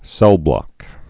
(sĕlblŏk)